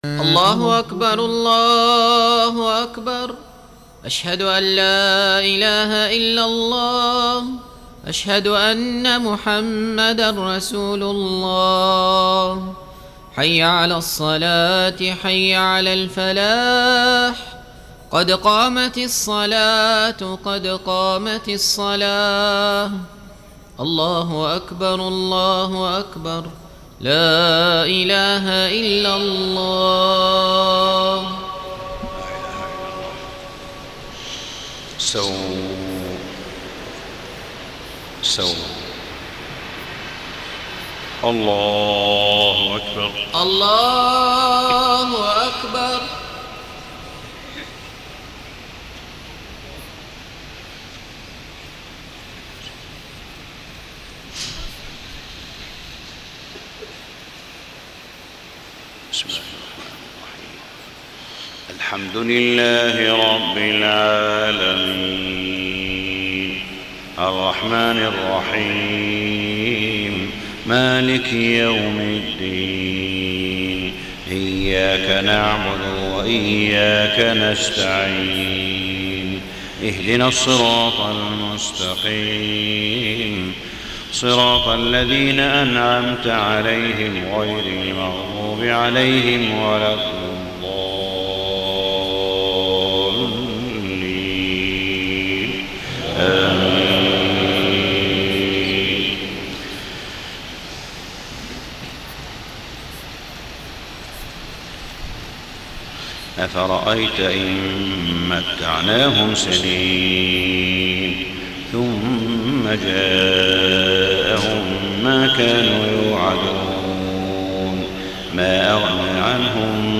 صلاة الفجر 6-9-1434 من سورتي الشعراء و السجدة > 1434 🕋 > الفروض - تلاوات الحرمين